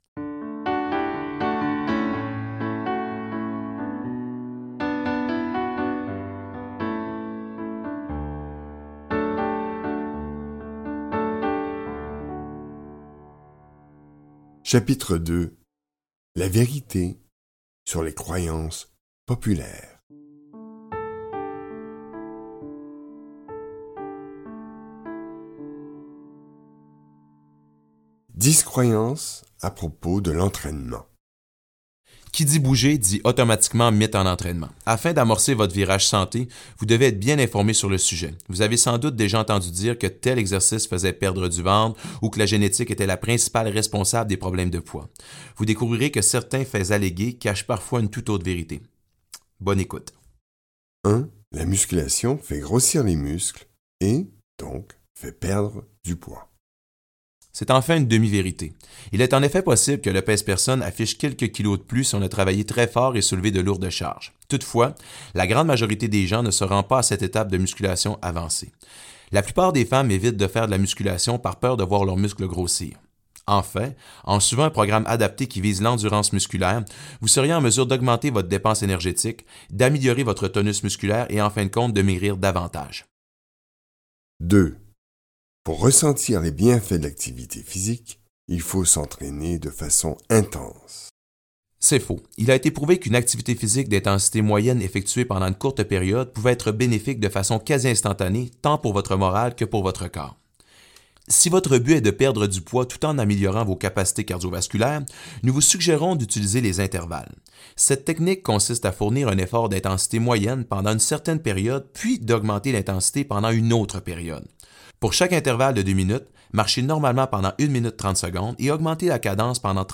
Lire un extrait Chantal Lacroix , Jimmy Sévigny , Chantal Lacroix , Jimmy Sévigny Maigrir La méthode S.O.S santé, ça marche ! Éditions Alexandre Stanké (audio) Date de publication : 2015-01-29 Ce livre audio écrit par Chantal Lacroix en collaboration avec Jimmy Sévigny s'adresse à tous ceux et celles qui désirent perdre du poids de façon saine et efficace. Il vous propose une méthode qui a fait ses preuves depuis plusieurs années.